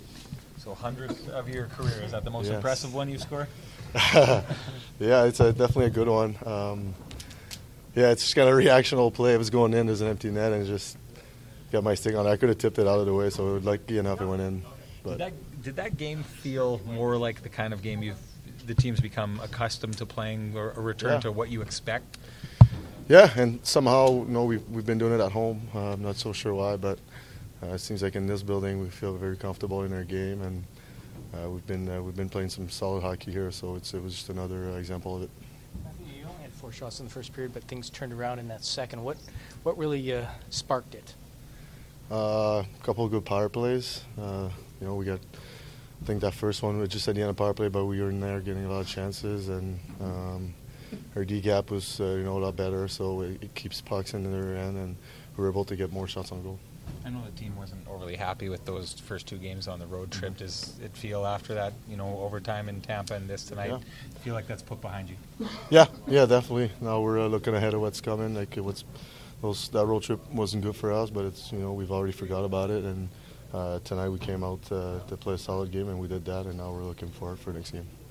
Post-game from the Jets and Canucks dressing rooms as well as from Coach Maurice.